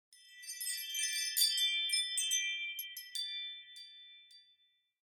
Ring Motion Notifications.ogg